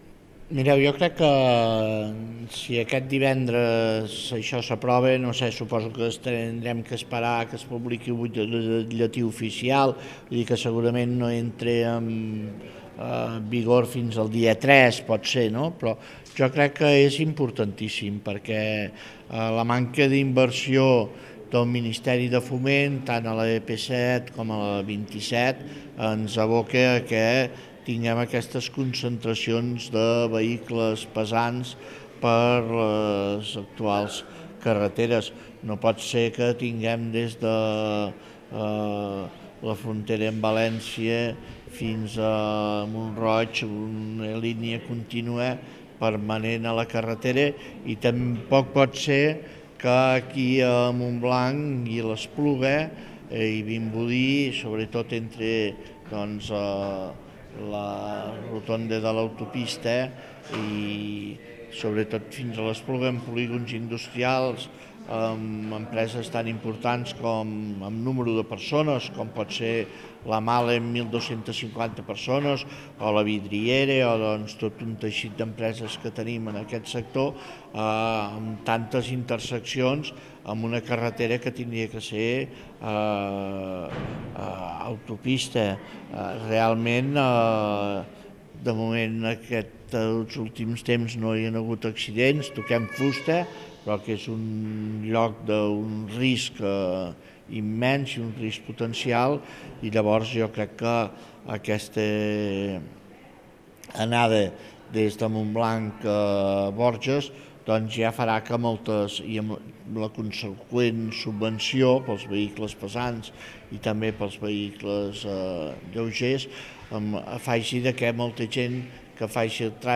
L’alcalde de Montblanc, Josep Andreu, ha valorat de manera positiva l’entrada en vigor aquest de les bonificacions pel desviament obligatori de camions de l’N-240 a l’Ap-2, entre Montblanc i les Borges Blanques. Assegura que és «molt important» la mesura, que ajudarà a descongestionar la carretera nacional, que a més és un tram amb una alta accidentalitat.
ÀUDIO: Josep Andreu parla de la mesura aprovada aquest divendres
Josep-Andreu.wav